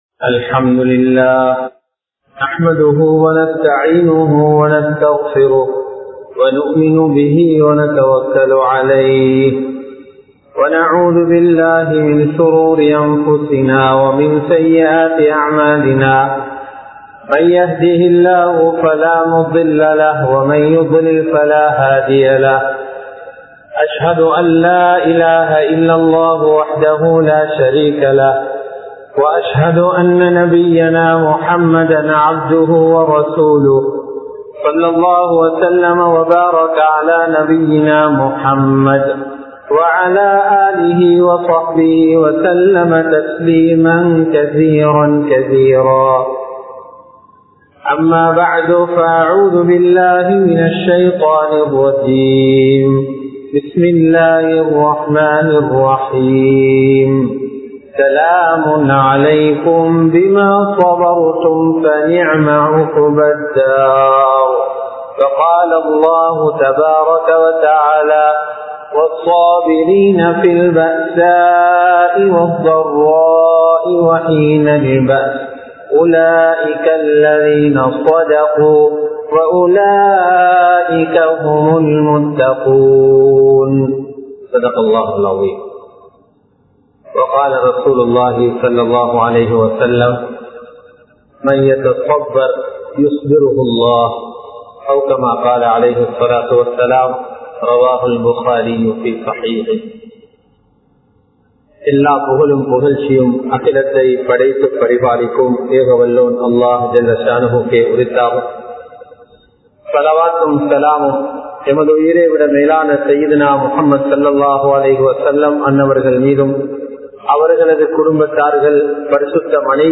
இது சோதனையா? தண்டனையா? (Is This A Test? Punishment?) | Audio Bayans | All Ceylon Muslim Youth Community | Addalaichenai
Kollupitty Jumua Masjith